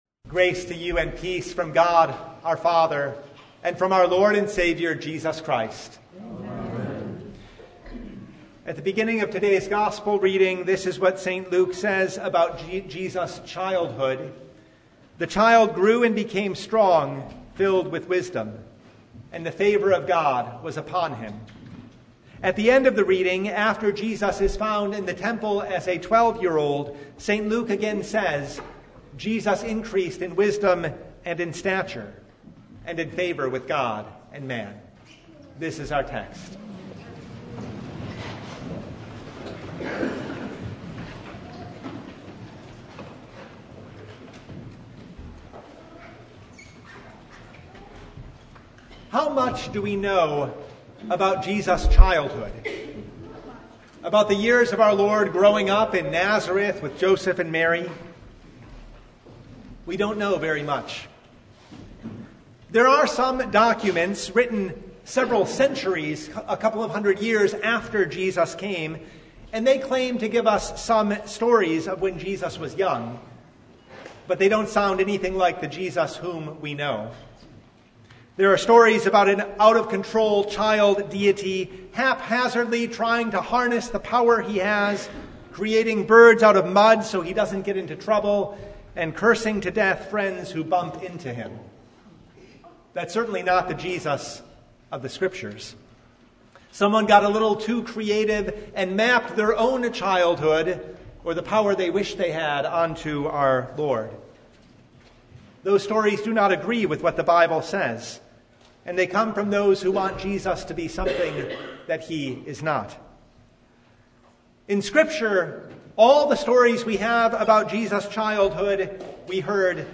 Luke 2:40–52 Service Type: Sunday On the eleventh day of Christmas